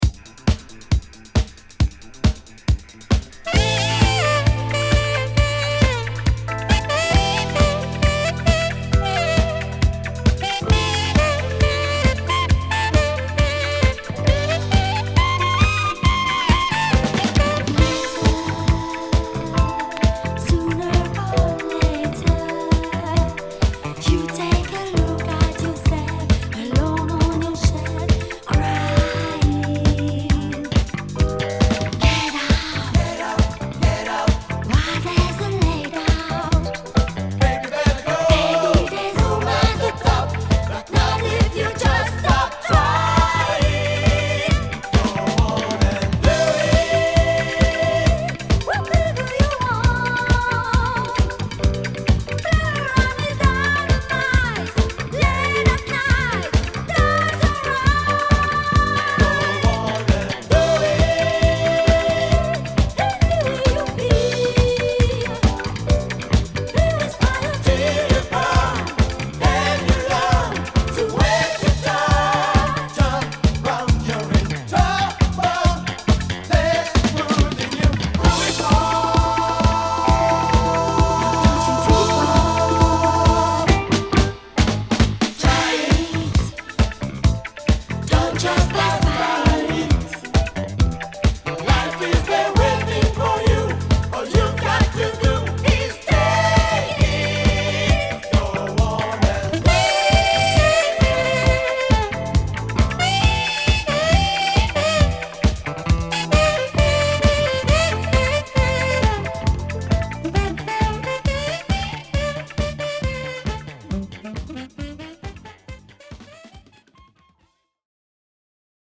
詳細不明のベルギー産哀愁Disco!!